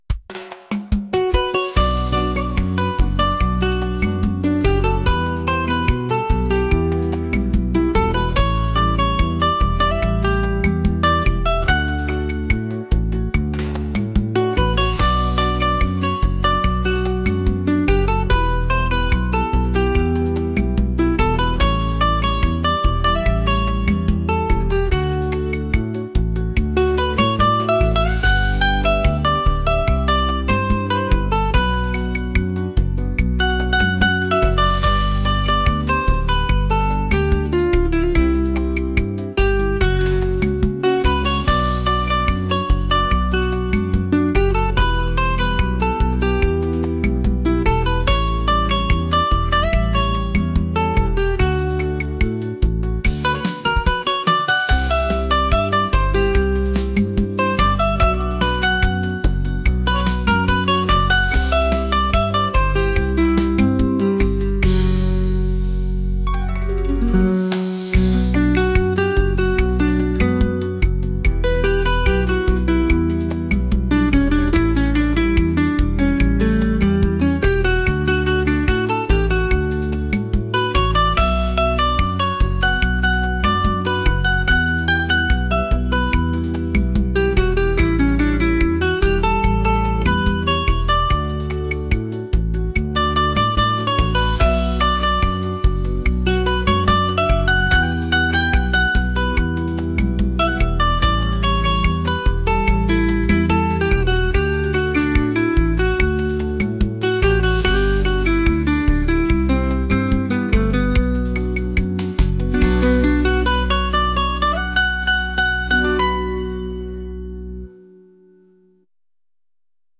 romantic | latin